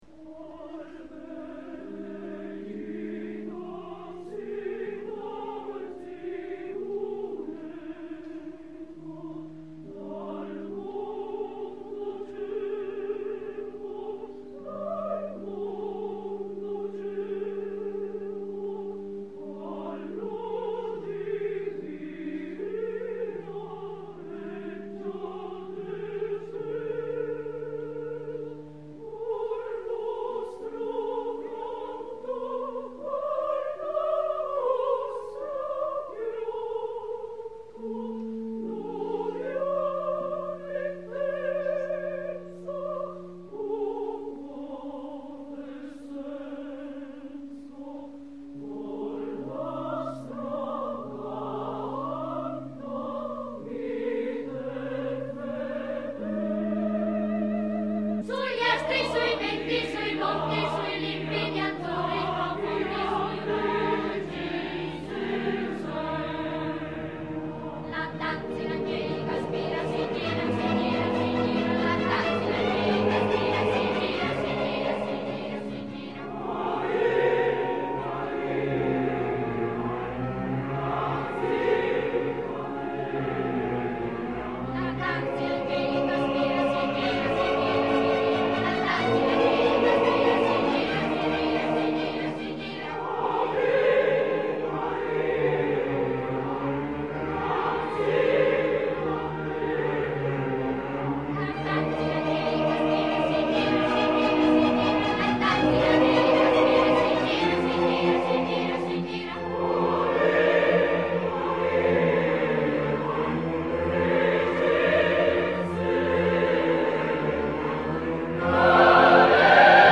opera completa, registrazione in studio.